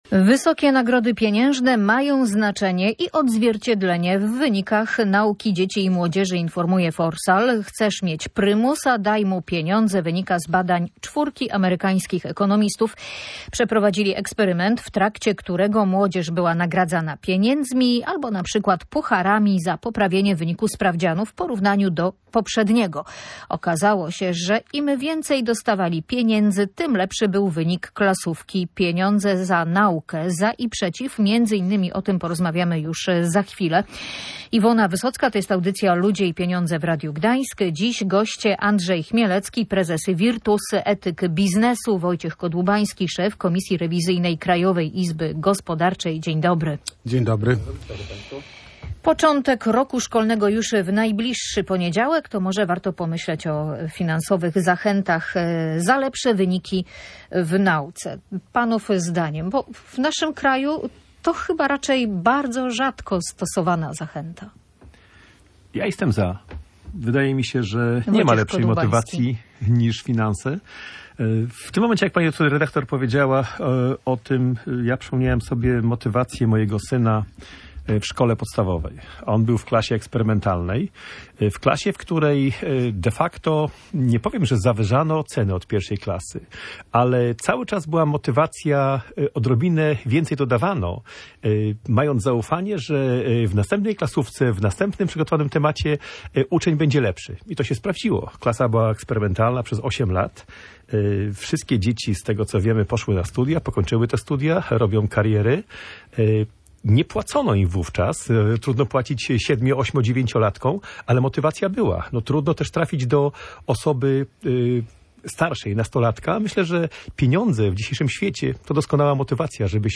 O tym sposobie dyskutowali nasi eksperci.